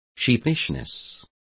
Complete with pronunciation of the translation of sheepishness.